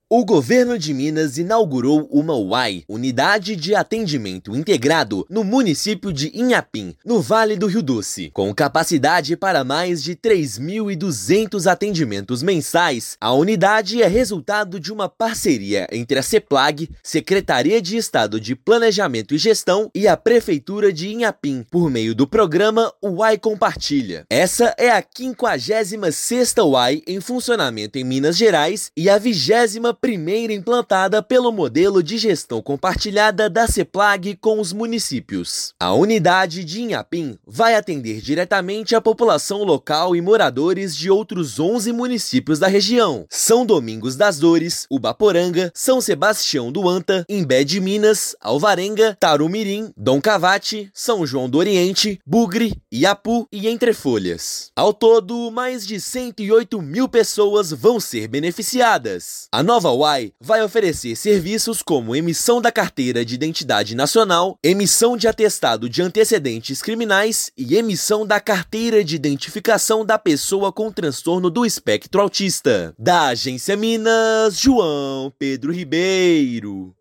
Unidade é a 56ª do estado e beneficiará mais de 108 mil cidadãos de 12 municípios da região com serviços essenciais. Ouça matéria de rádio.